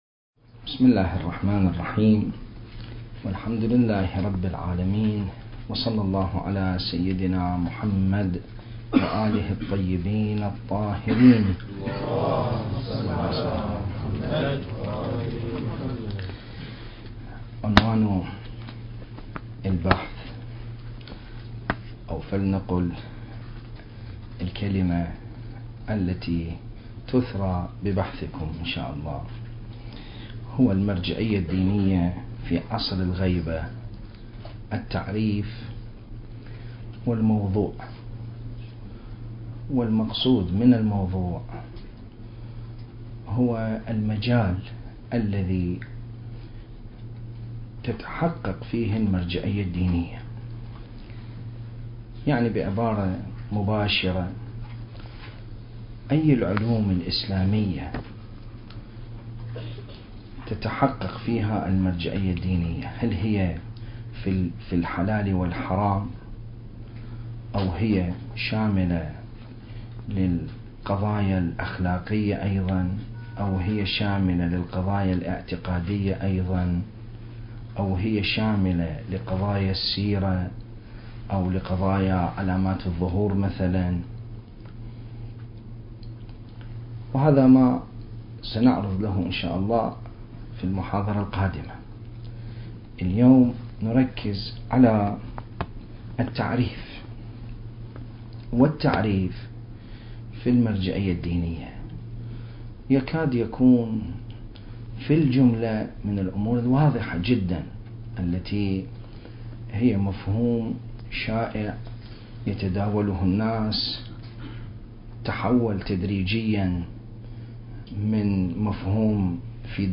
عنوان البحث: المرجعية الدينية في عصر الغيبة الكبرى (1) الندوة المهدوية بمناسبة الشهادة الفاطمية الثانية والتي عقدت تحت شعار (ولي في ابنة رسول الله اسوة حسنة) المكان: قاعة مركز الدراسات التخصصية في الإمام المهدي (عجّل الله فرجه) في النجف الاشرف التاريخ: 2016